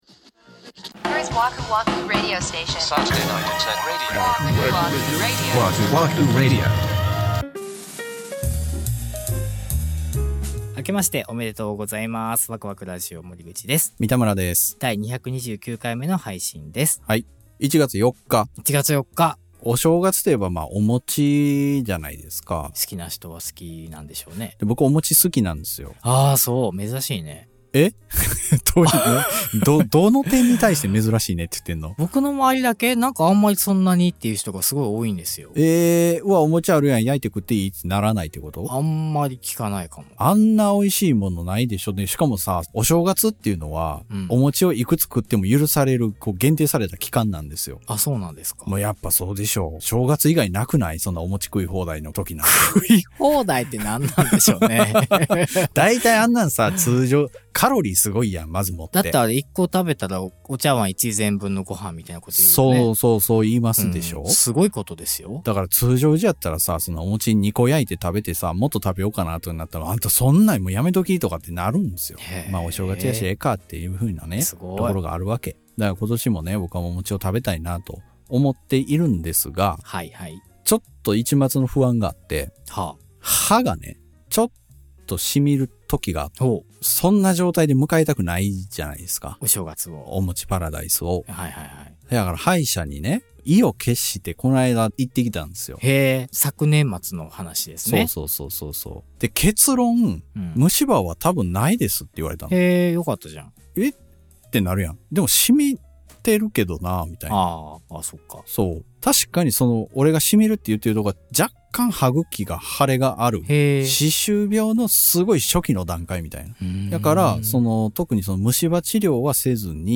日常観察家事情報人生共感型 ジャスト10分バラエティ。 シュッとしたおっちゃんになりきれない、 宙ぶらり世代の２人が、 関西からお送りしています。